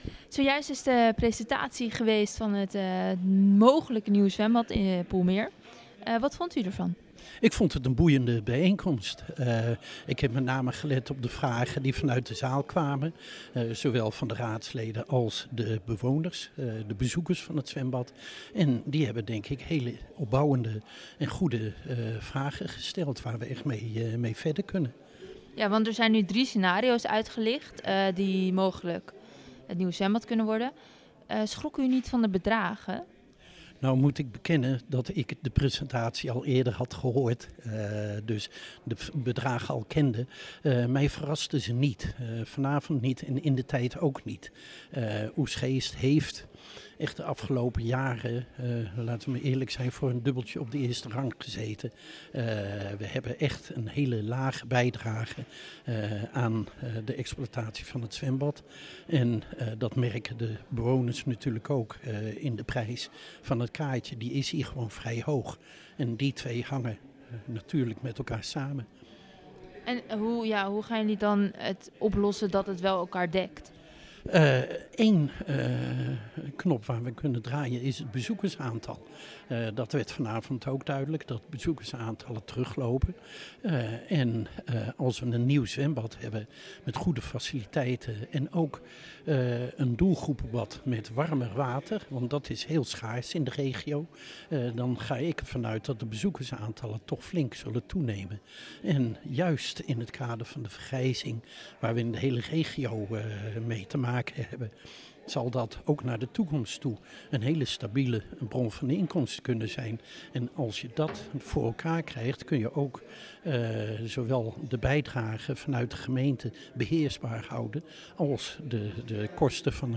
Wethouder Tim van Tongeren over het lopende onderzoek naar de mogelijkheden voor een nieuwzwembad